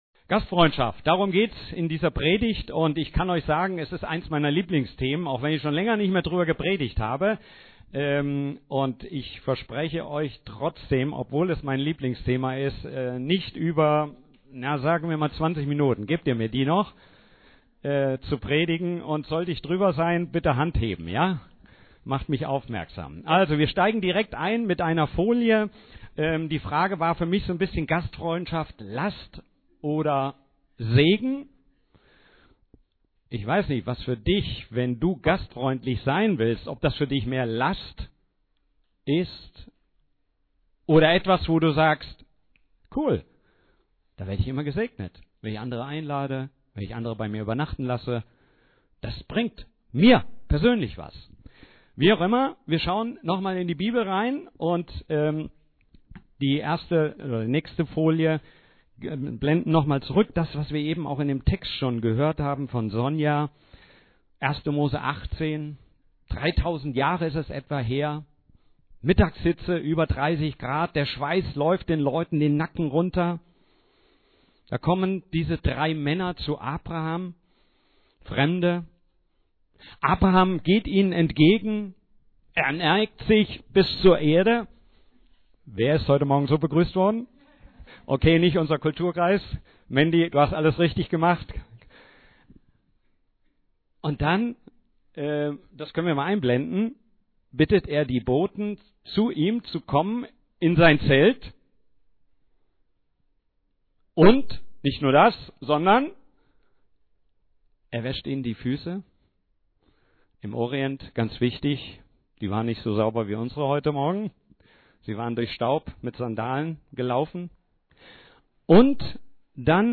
Hier sind die Predigten der wöchentlichen Gottesdienste der FeG Schwerin.